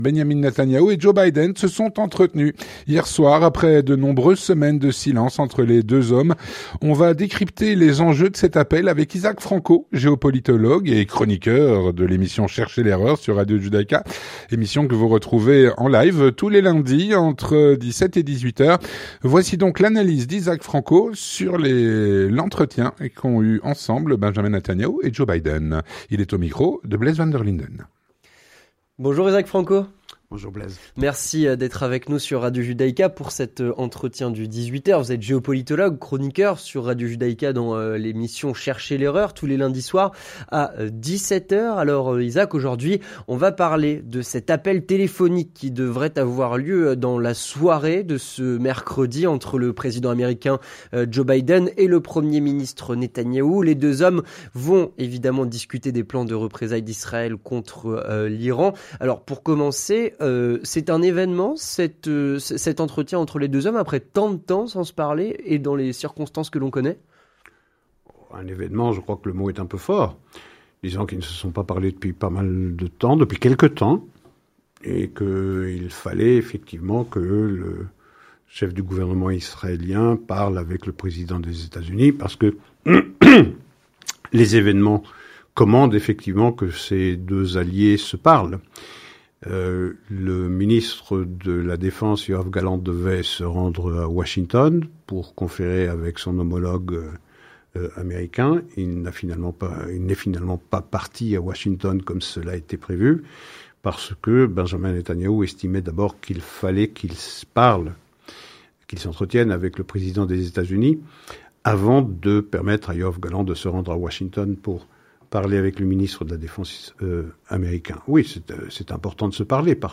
Entretien téléphonique entre B. Netanyahou et J. Biden.